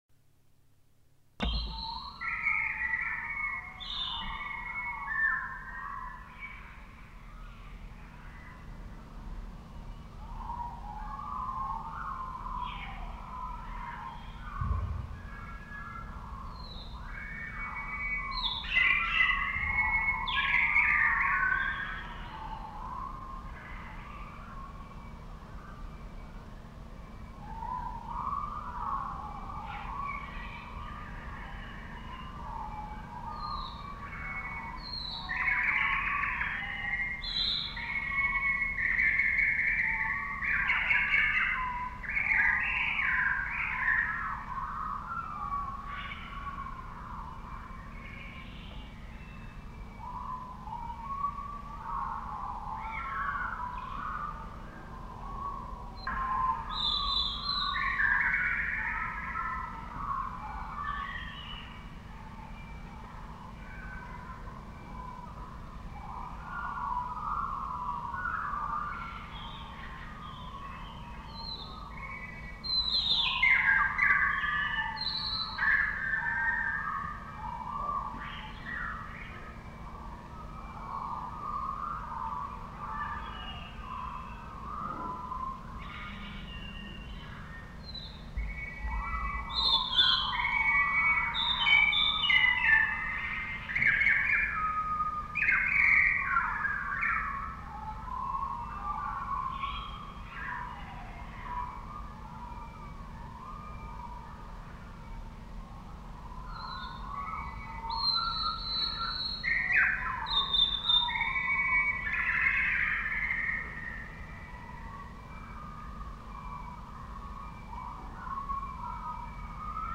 Chants d'oiseaux et bruits d'eau
Genre : paysage sonore